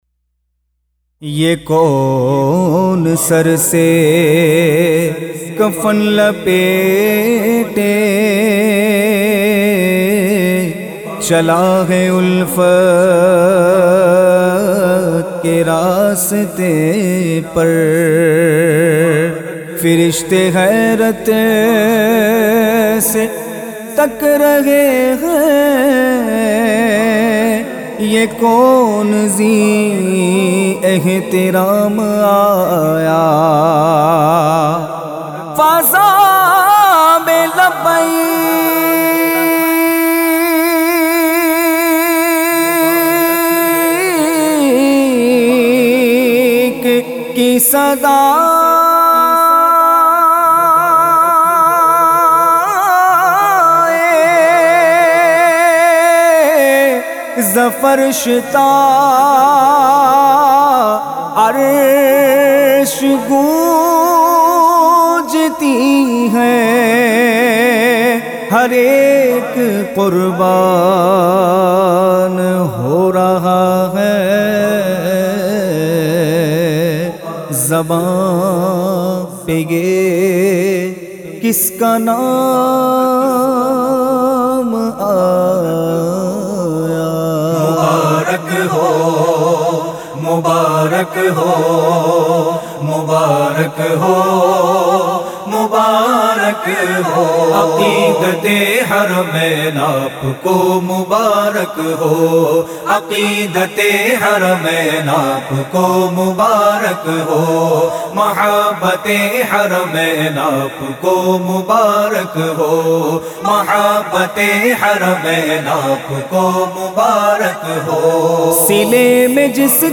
کلام